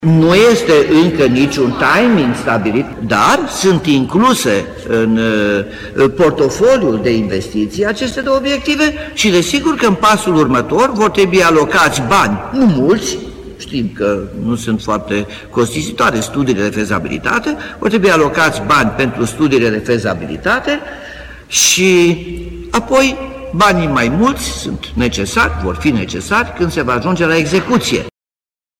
Primarul Nicolae Robu a subliniat că deocamdată nu se ştie când vor fi alocaţi banii pentru investiţii.
ora-1200-Nicolae-Robu-investitii-2.mp3